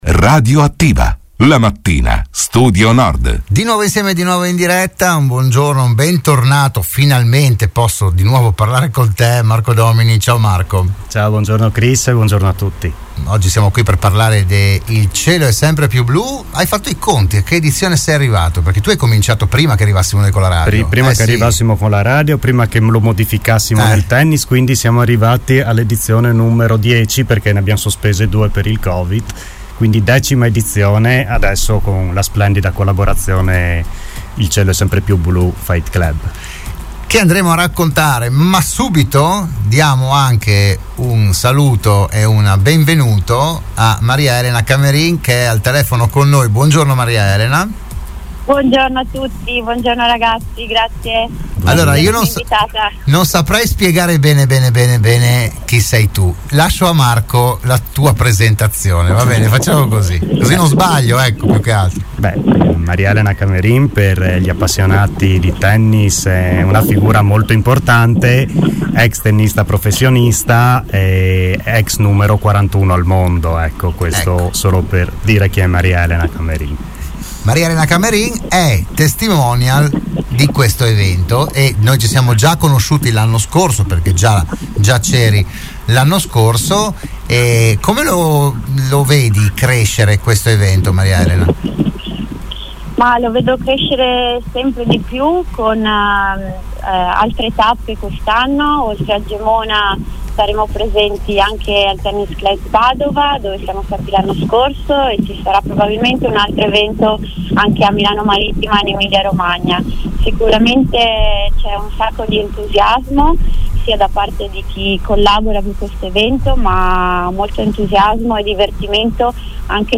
ospiti della trasmissione "RadioAttiva"